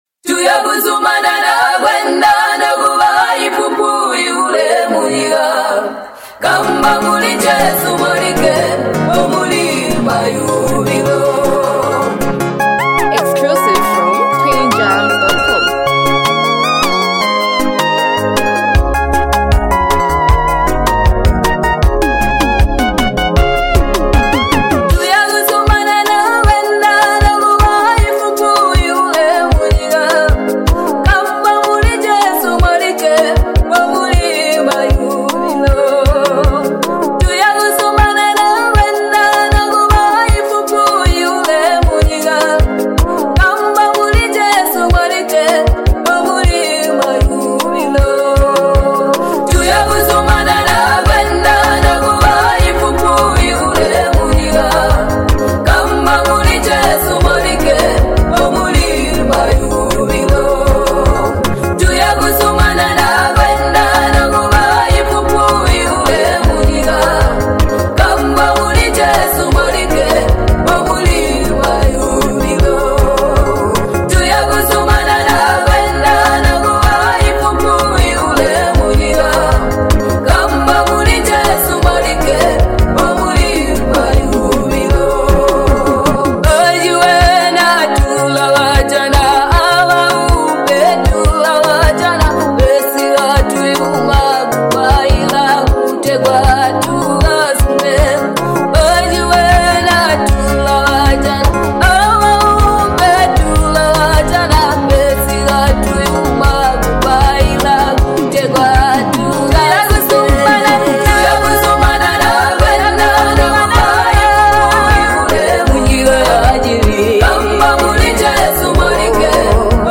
MusicGospel
Zambian Gospel Music